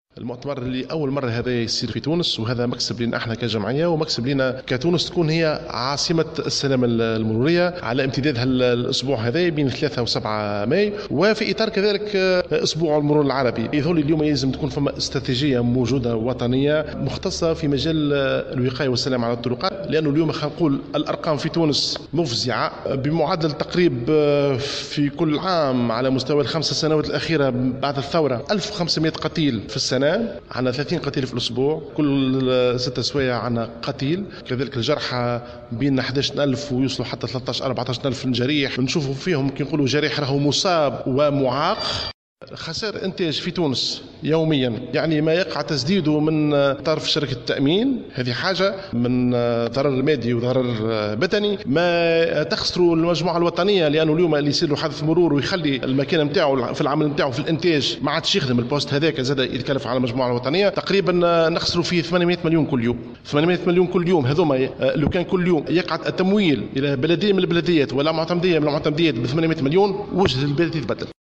Dans une déclaration accordée à la correspondante de Jawhara FM